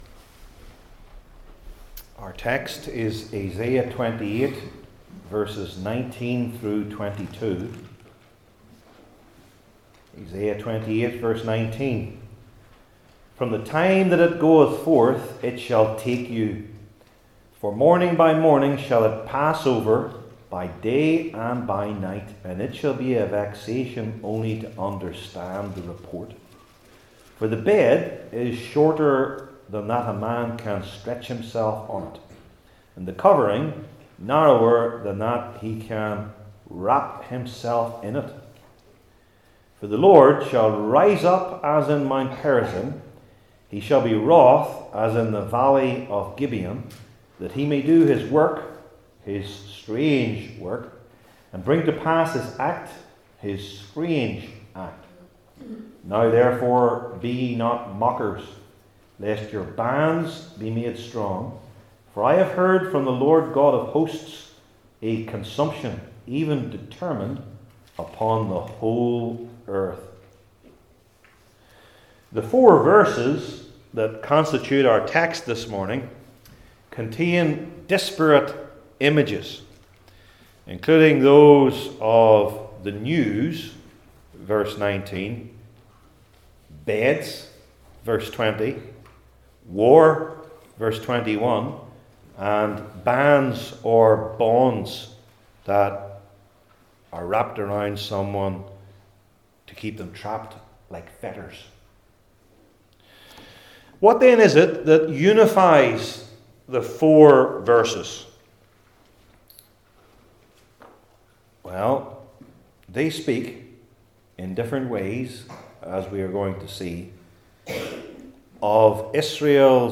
28-32 Service Type: Old Testament Sermon Series I. Bad News II.